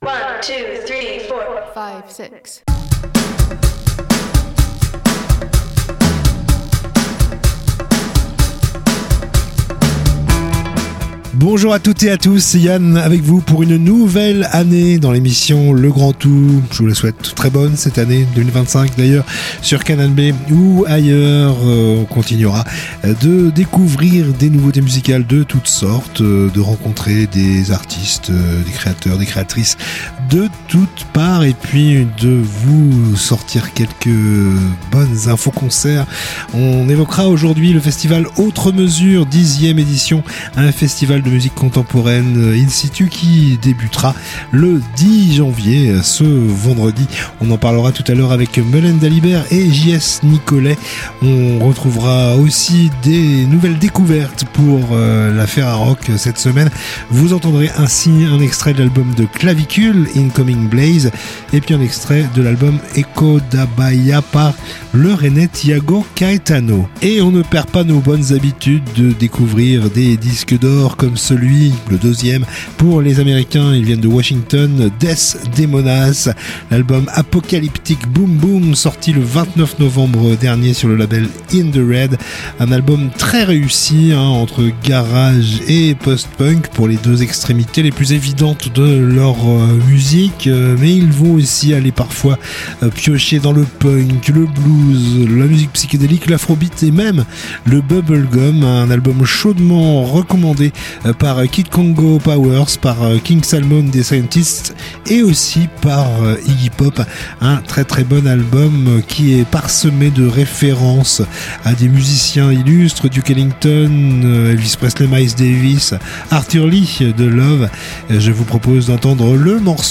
itv musique + infos-concerts